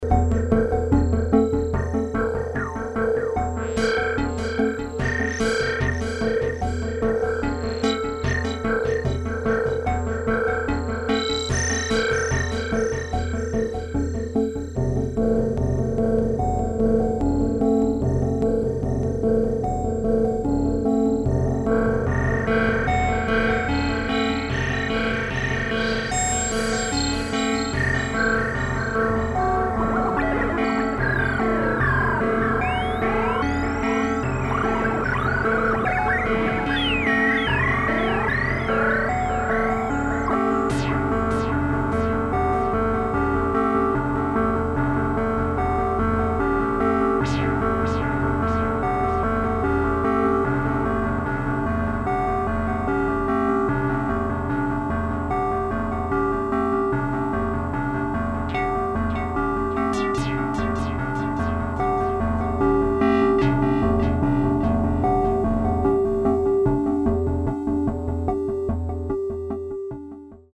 prototype electronic musical instruments
Unlike the self destructing vacuum tube circuits the Barrons used, the Martian Music Machines are solid state integrated analog synthesizers similar to the instruments made during the mid 1960's by Bob Moog and Don Buchla. Many of the complex tonal modulation sounds seem to take on lives of their own as they sing or sometimes scream in and out of existence.